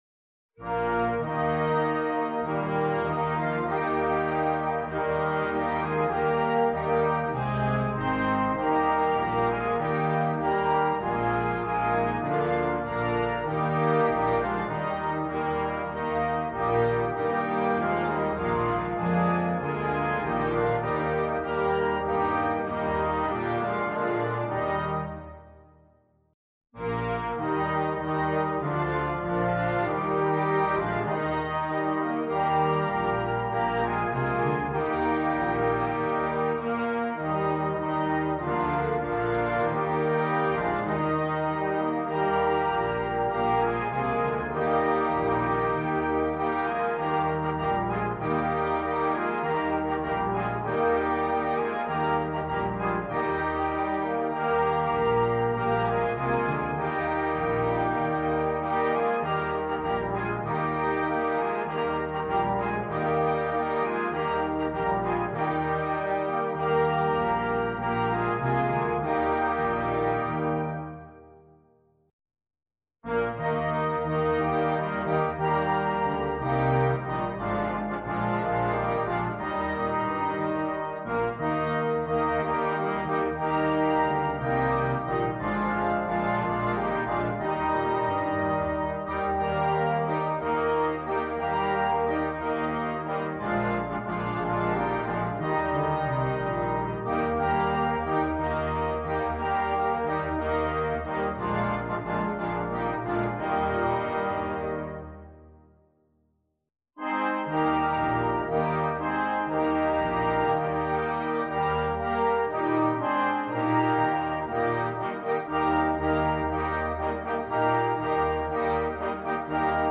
Komponist: Traditionell
Besetzung: Blasorchester